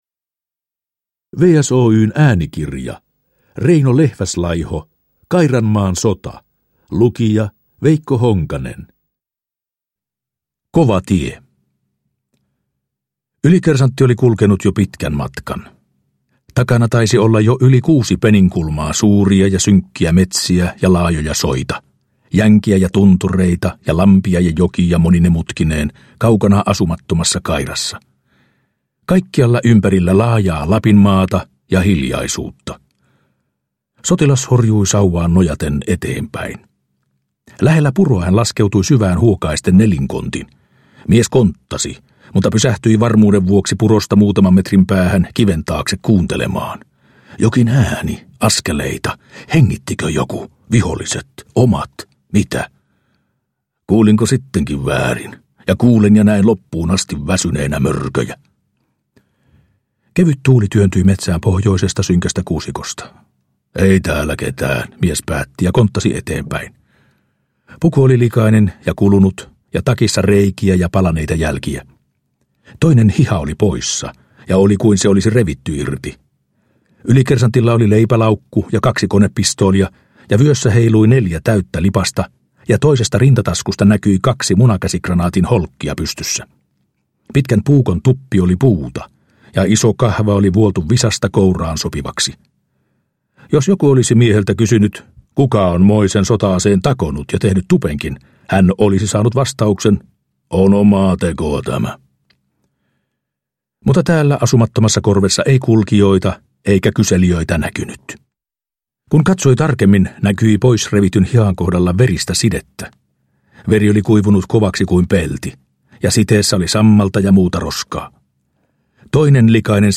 Historia Njut av en bra bok Romaner